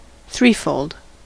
threefold: Wikimedia Commons US English Pronunciations
En-us-threefold.WAV